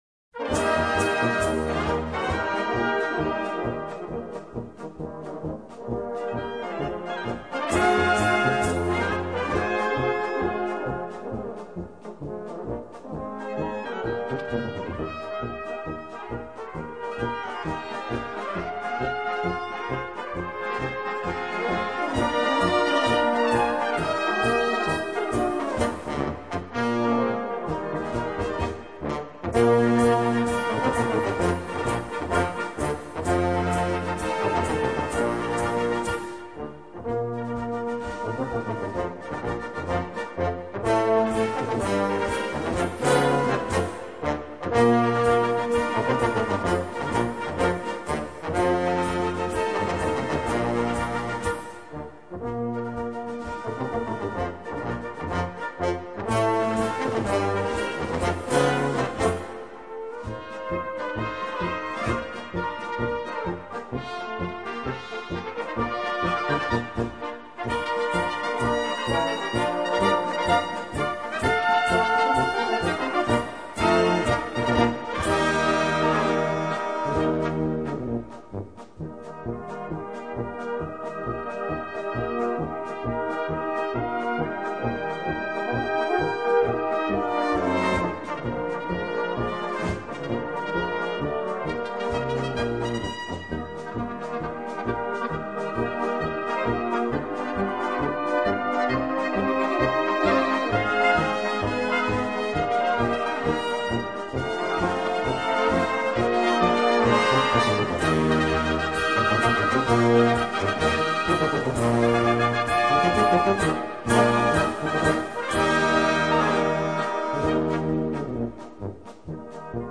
Gattung: Böhmischer Marsch
Besetzung: Blasorchester
Ein schwungvoller Auftakt für Ihr Unterhaltungskonzert!